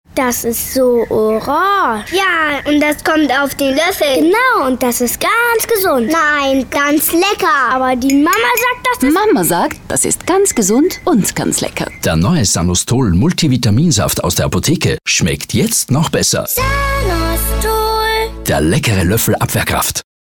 Sprecher, Moderator sowie ISO zertifizierter Kommunikations- u. Performancetrainer.
Sprechprobe: Sonstiges (Muttersprache):